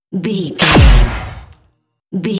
Dramatic hit electricity
Sound Effects
heavy
intense
dark
aggressive
hits